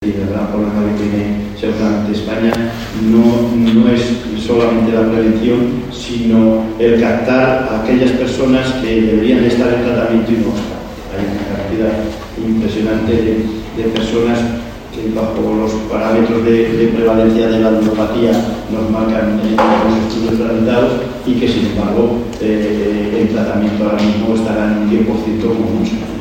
Mesa inaugural de las jornadas de Fejar